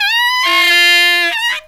63SAXFALL2-L.wav